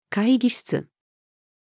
会議室-female.mp3